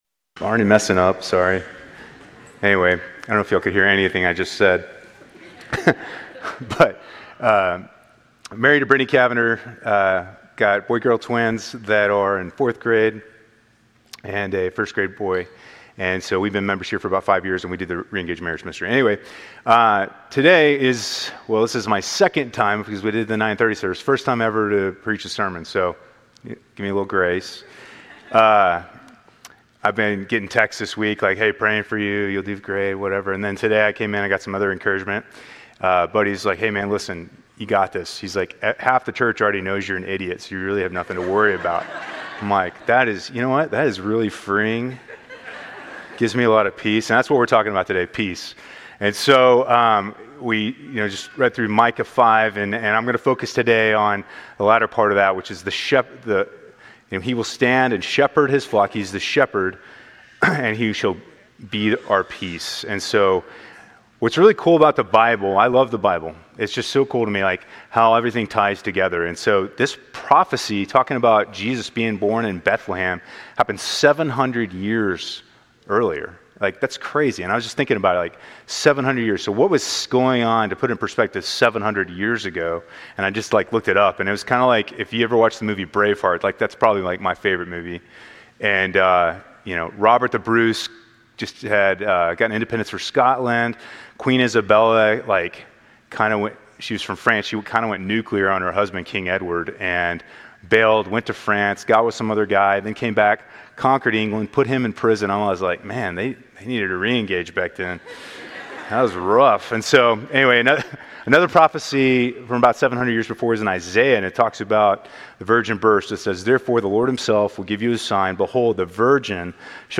Grace Community Church Lindale Campus Sermons 12_7 Lindale Campus Dec 08 2025 | 00:27:54 1x 00:00 / 00:27:54 Subscribe Share RSS Feed Share Link Embed